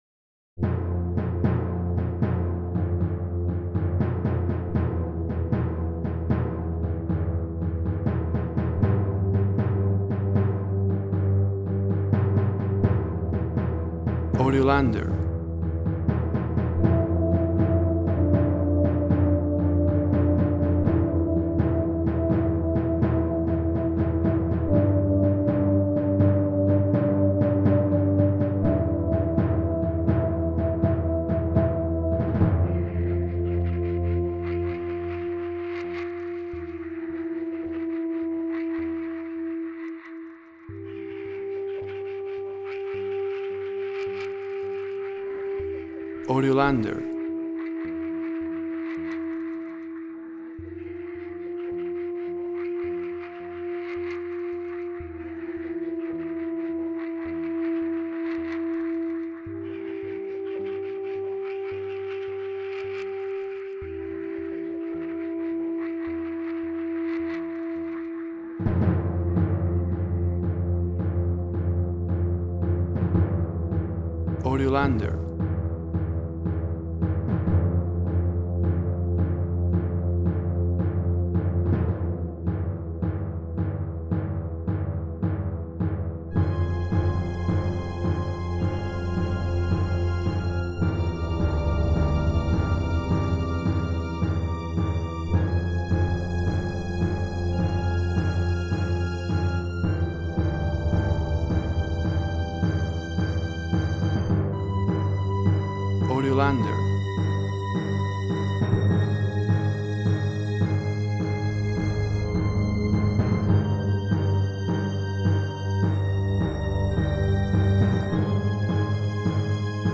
Incidental work for moments of terror and suspense.
Tempo (BPM) 90